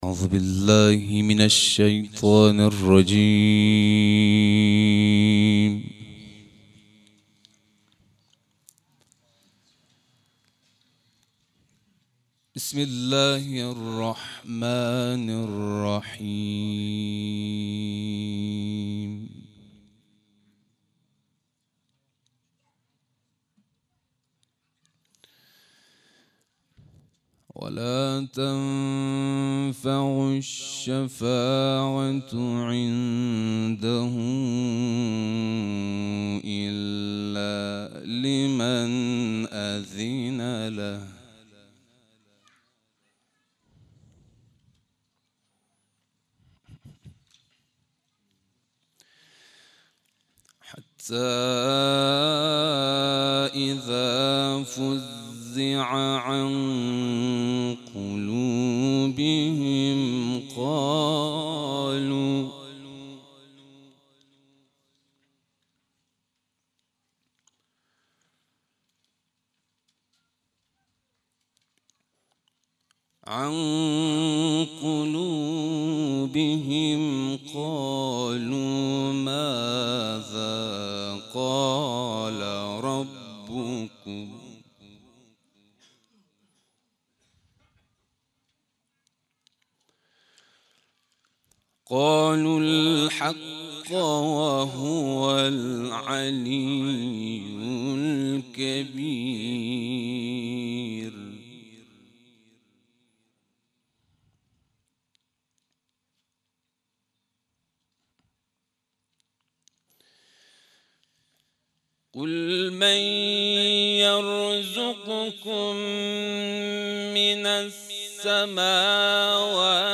قرائت قرآن